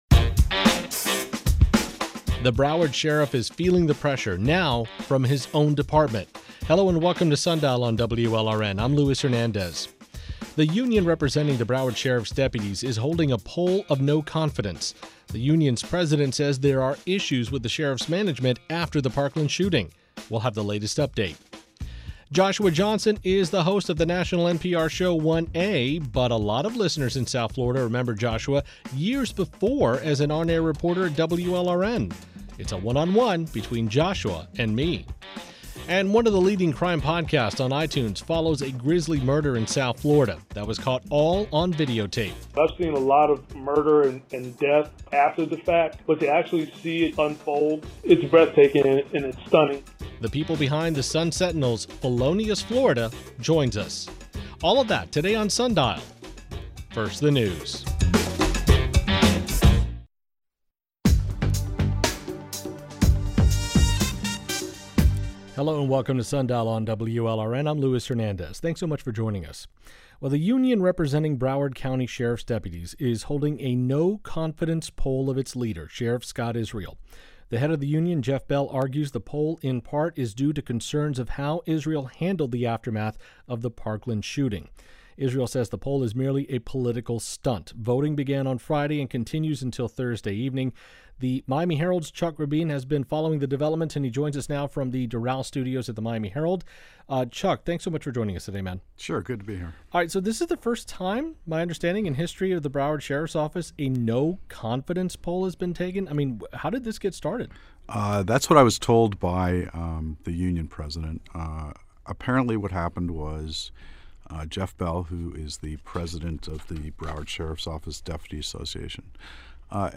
Joshua Johnson is the host of the NPR show 1A.